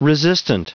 Prononciation du mot resistant en anglais (fichier audio)
Prononciation du mot : resistant